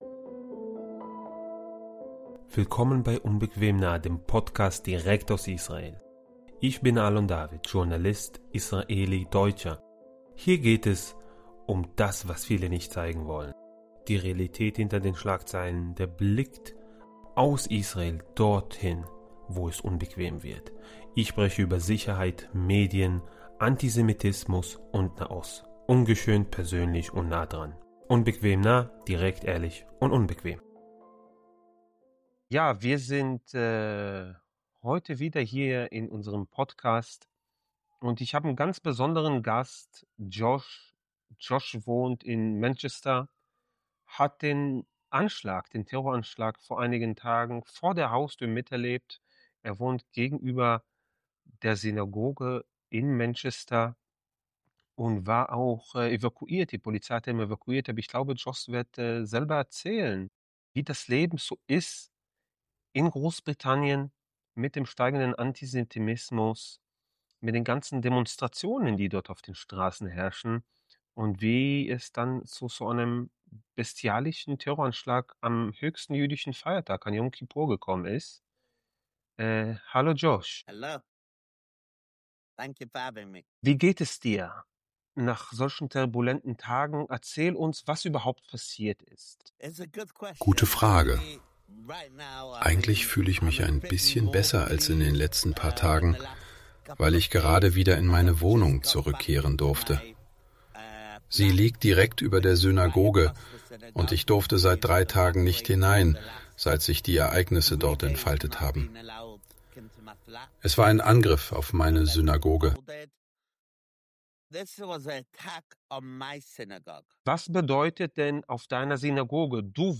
Die deutsche Übersetzung wurde mithilfe einer KI-Stimme eingesprochen.